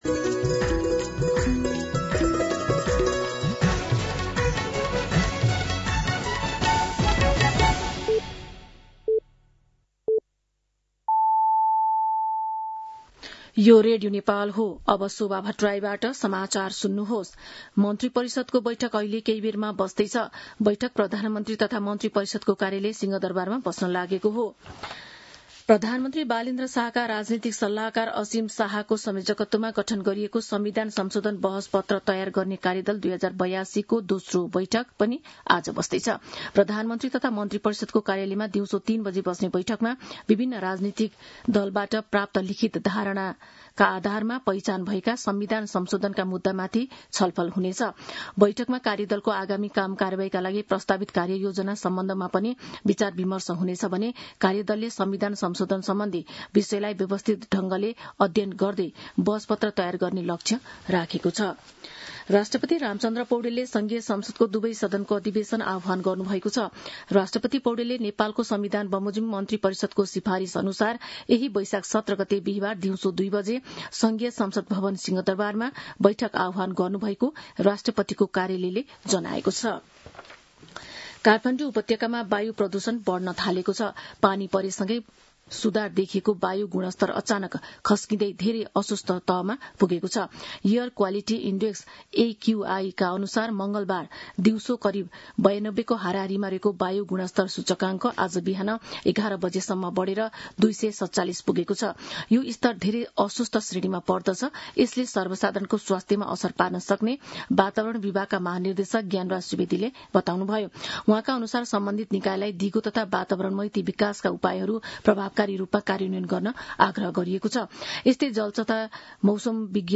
An online outlet of Nepal's national radio broadcaster
मध्यान्ह १२ बजेको नेपाली समाचार : १० वैशाख , २०८३
12-pm-News-1-10.mp3